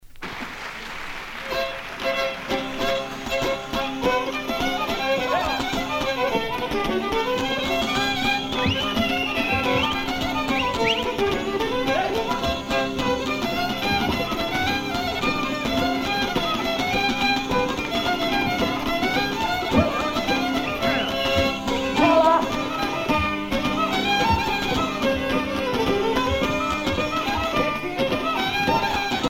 Syrtos
danse : syrtos (Grèce)